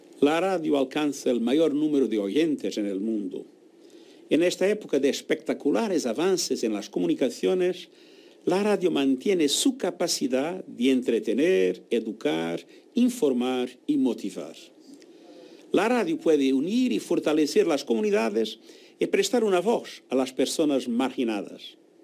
Antonio Guterres, Secretario General de la ONU:
Antonio-Guterres-sobre-la-radio-2.mp3